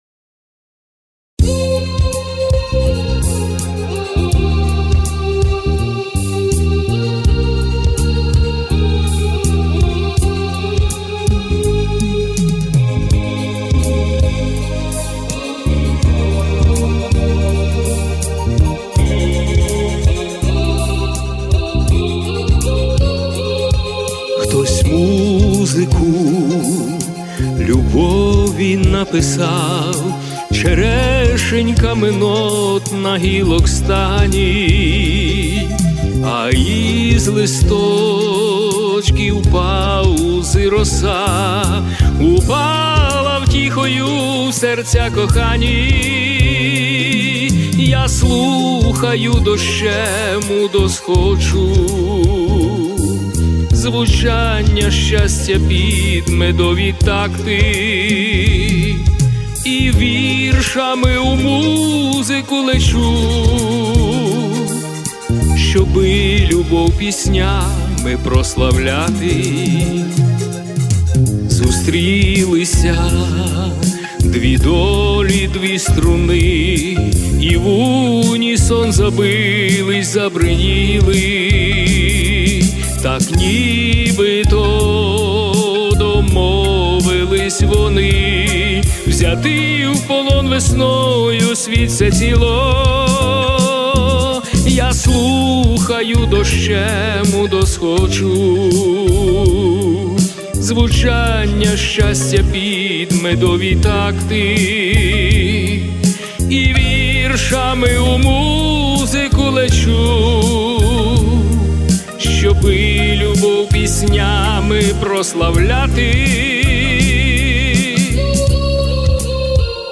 Рубрика: Поезія, Лірика кохання
12 гарна пісня і виконання friends